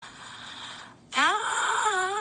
siri-m-o-a-n-1.mp3